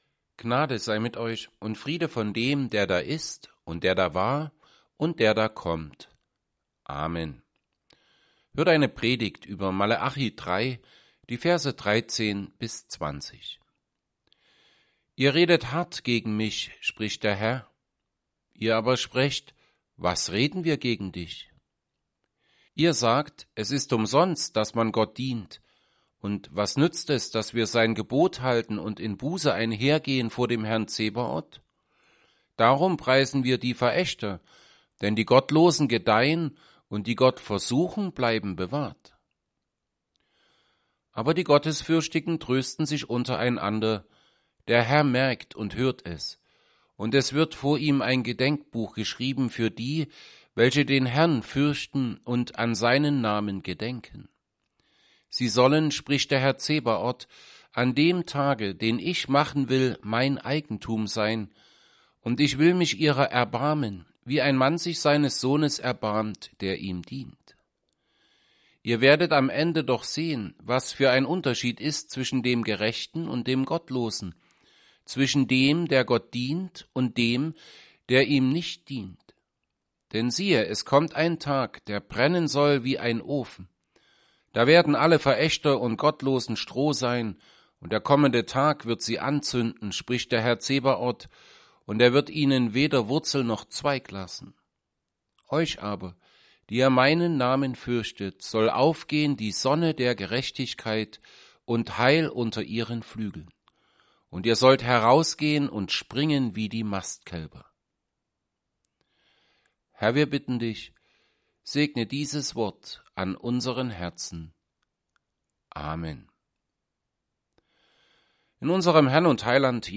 Predigt_zu_Maleachi_3_13b20.mp3